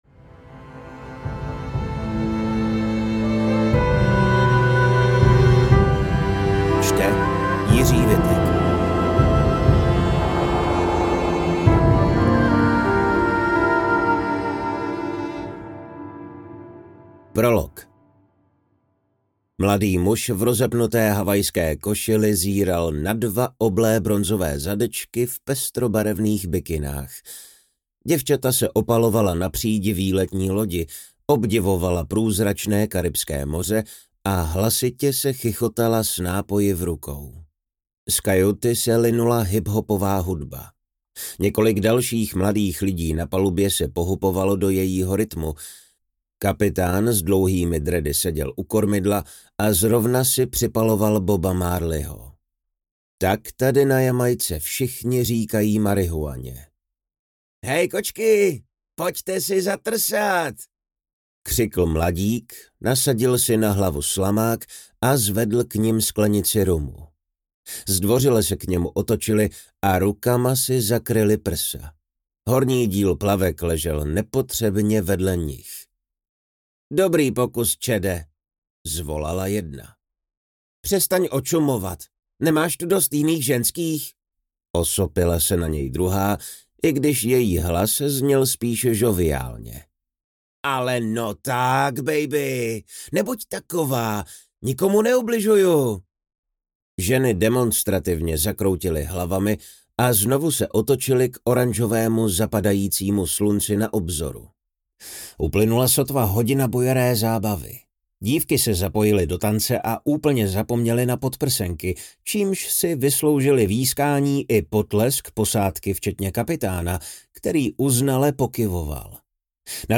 Prokletý záliv audiokniha
Ukázka z knihy
proklety-zaliv-audiokniha